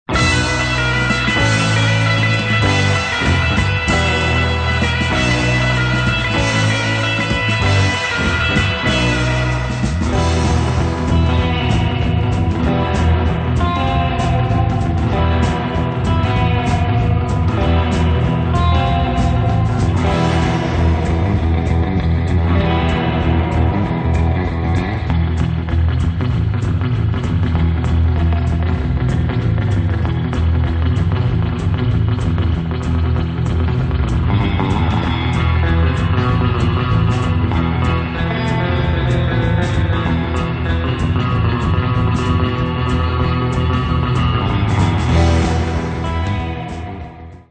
exciting fast instr.